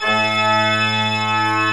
Index of /90_sSampleCDs/AKAI S6000 CD-ROM - Volume 1/VOCAL_ORGAN/CHURCH_ORGAN
ORG D2MF  -S.WAV